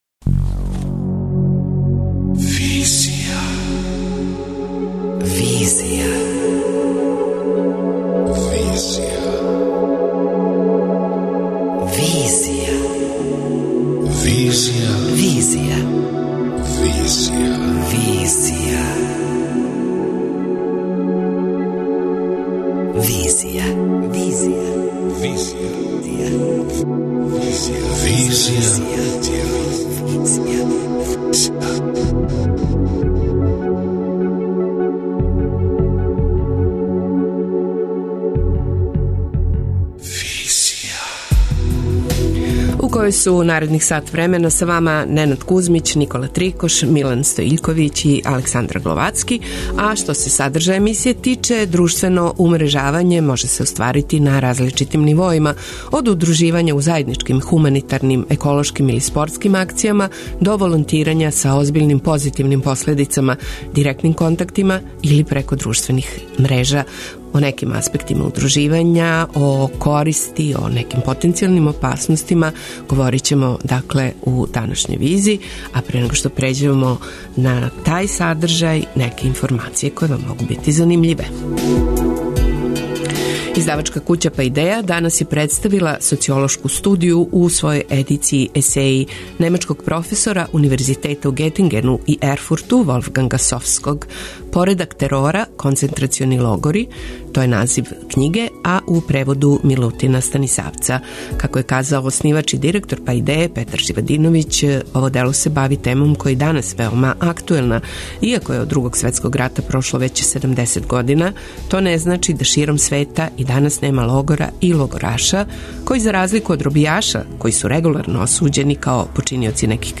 преузми : 26.84 MB Визија Autor: Београд 202 Социо-културолошки магазин, који прати савремене друштвене феномене.